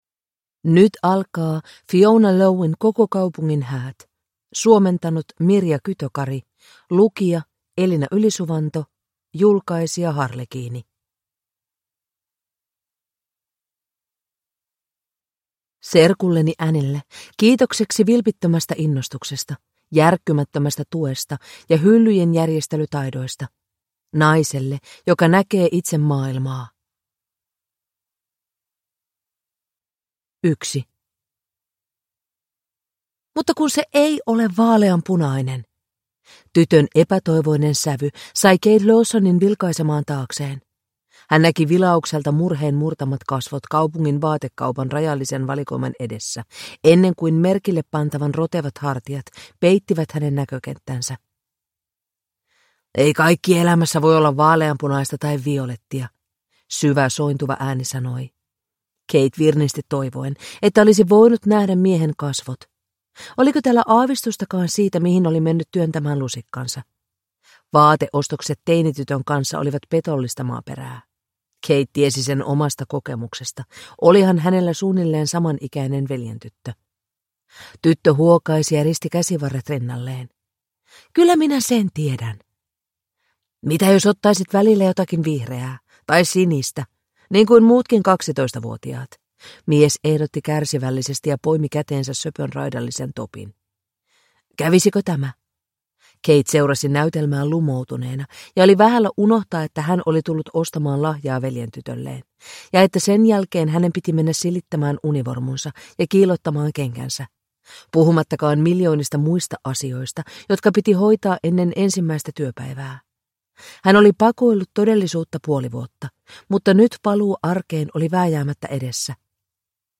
Koko kaupungin häät (ljudbok) av Fiona Lowe